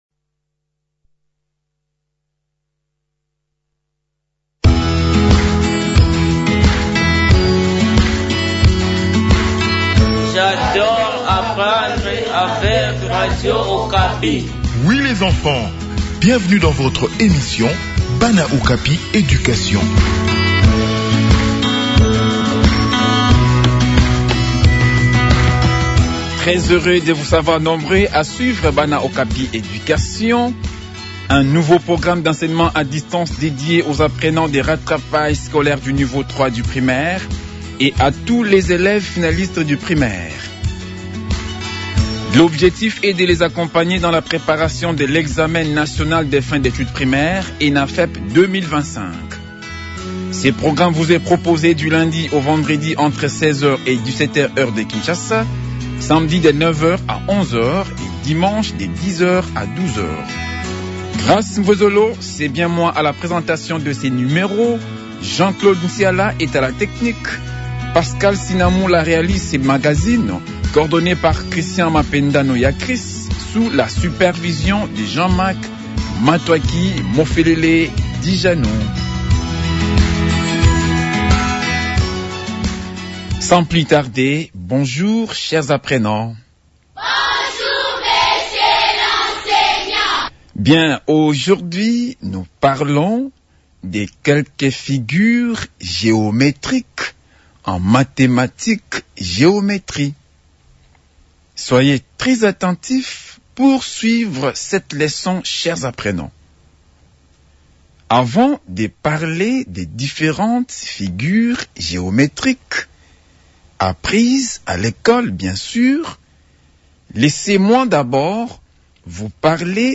Préparation aux examens nationaux : leçon sur les sortes des fractions en Maths/ Numération